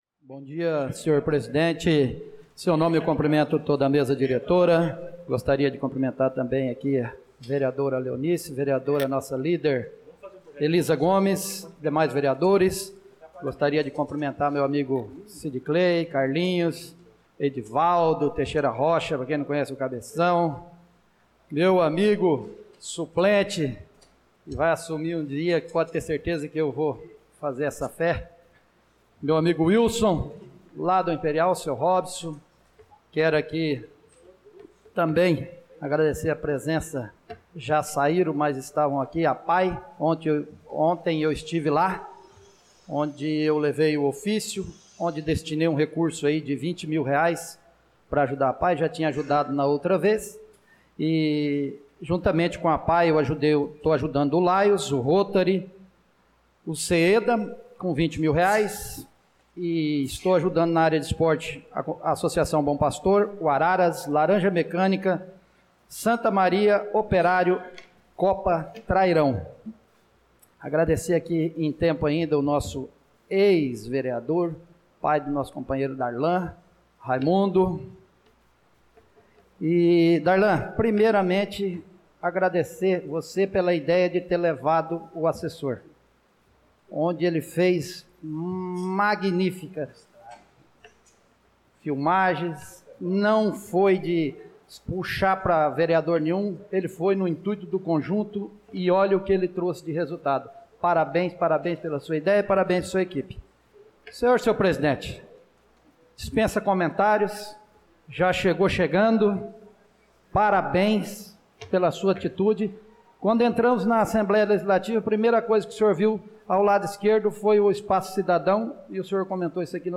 Pronunciamento do vereador Marcos Menin na Sessão Ordinária do dia 18/02/2025